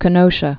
(kə-nōshə)